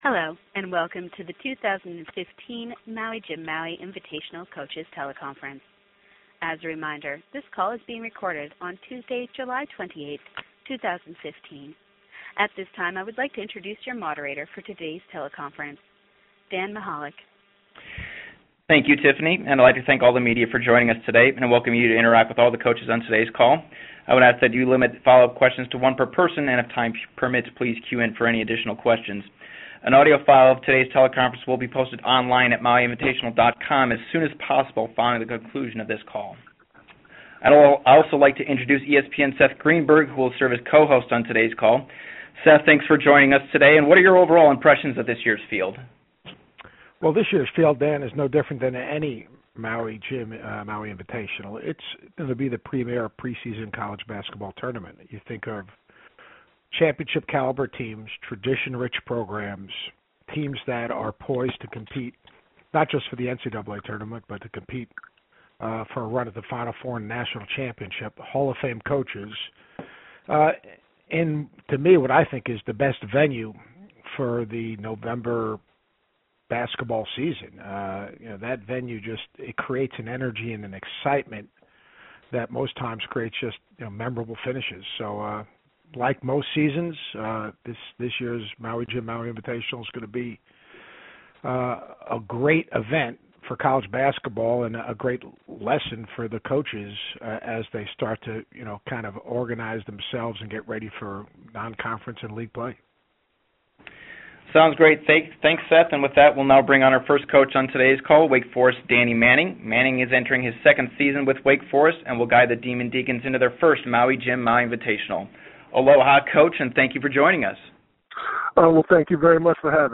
Story Links Maui Jim Maui Invitational Bracket Announcement Coaches Teleconference MAUI, HAWAII (July 28, 2015) – The Maui Jim Maui Invitational today announced its 2015 Championship Round bracket.